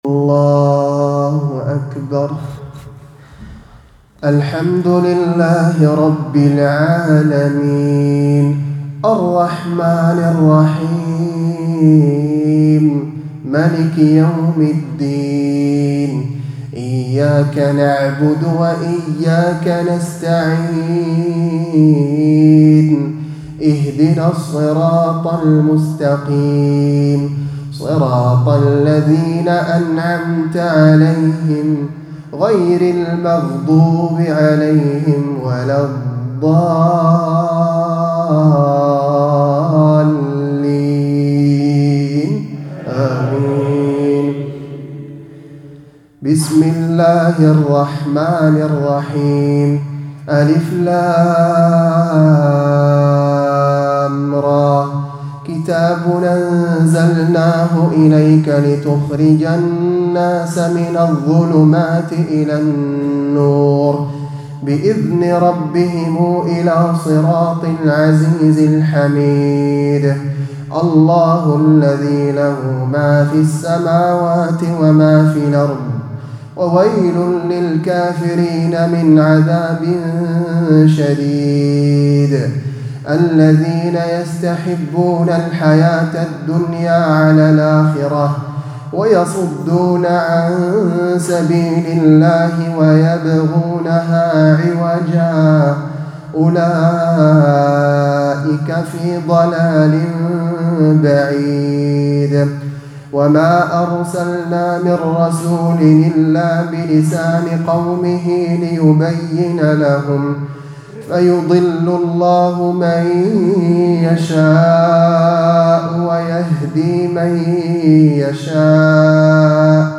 تلاوتي من تراويح العام الماضي.من سورة إبراهيم.اللهم بلغنا رمضان